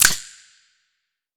TC3Snare24.wav